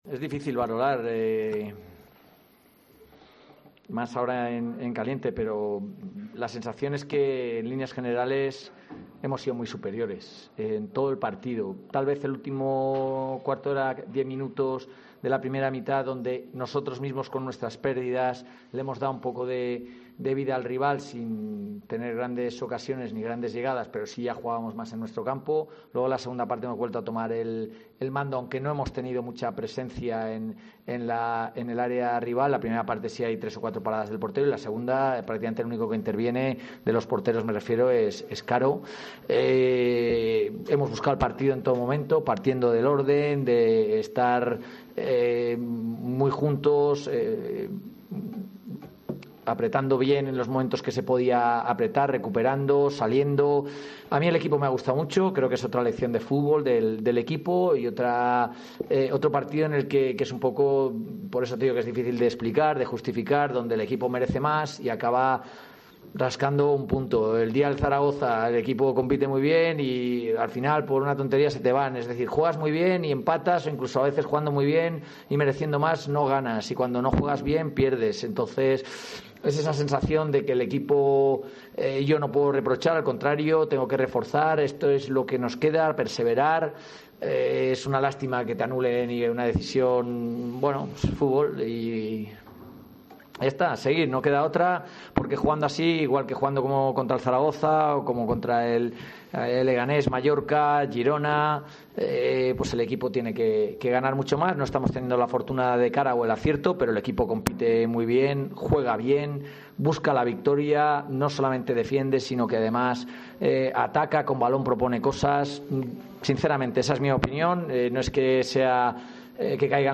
Postpartido Ponferradina - Fuenlabrada (0-0)